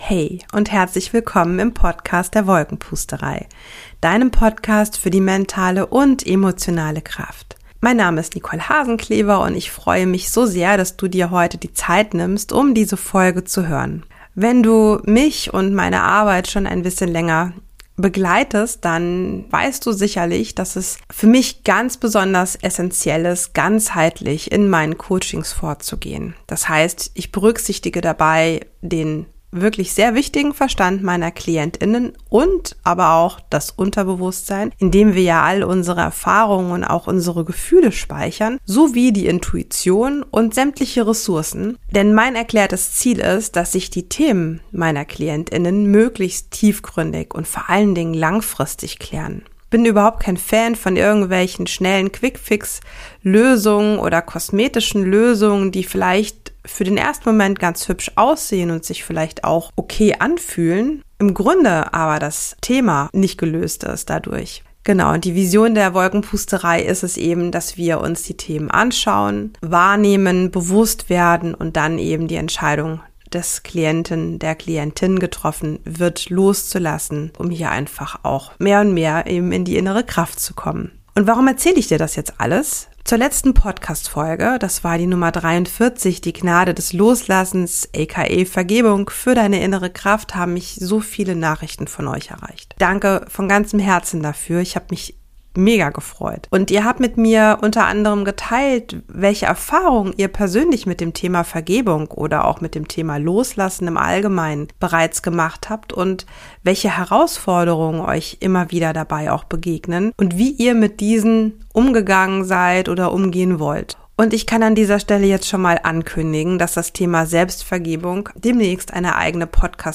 #44 Vergebung - geführte Meditation Mentalübung ~ Wolkenpusterei - Für deine mentale und emotionale Kraft Podcast
Beschreibung vor 9 Monaten In dieser Podcast-Folge erwartet dich eine kraftvolle meditative Mentalübung, in der du die Möglichkeit hast, in ganz entspanntem Tempo auf tieferer Ebene zu vergeben. Vielleicht trägst du schon lange einen Vorwurf in dir -vielleicht sogar gegenüber dir selbst - und spürst, dass es Zeit ist diesen loszulassen.